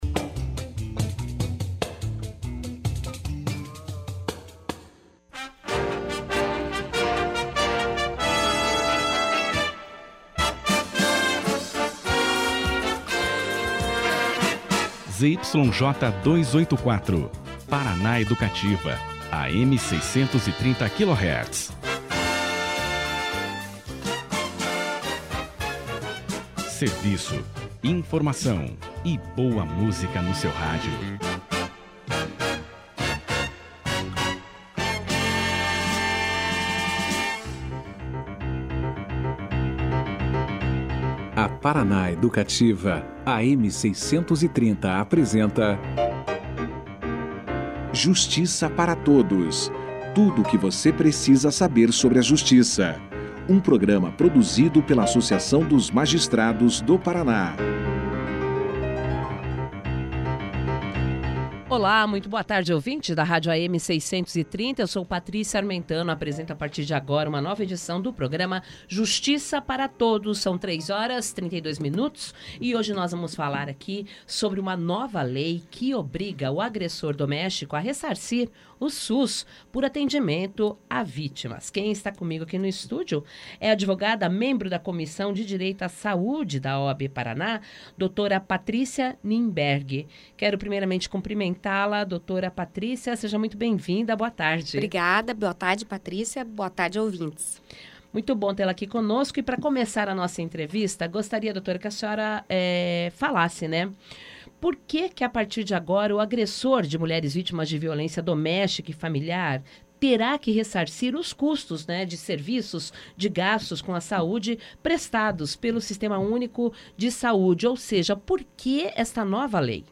A lei sancionada não exige condenação do agressor, e o ressarcimento pode ser no âmbito civil, mas não pode ser usado como atenuante para uma condenação penal. Confira aqui a entrevista na íntegra.